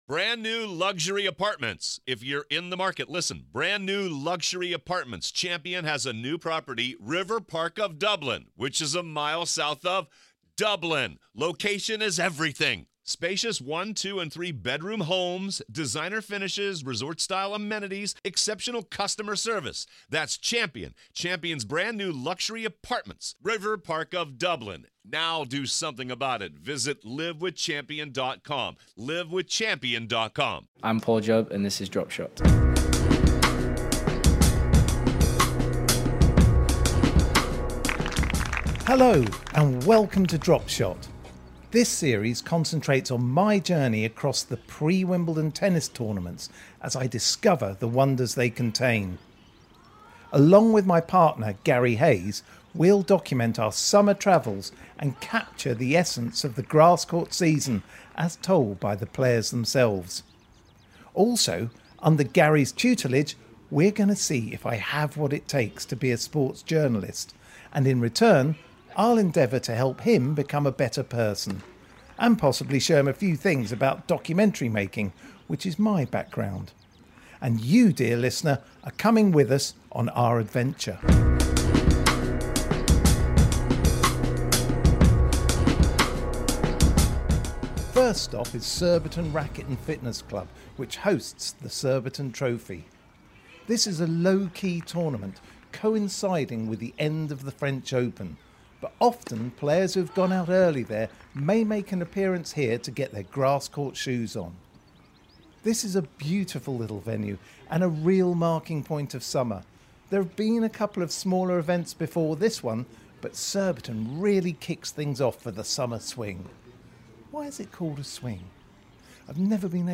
It all takes place in the unique atmosphere of a forgotten grass court summer in the UK.